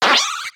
Cri de Mascaïman dans Pokémon X et Y.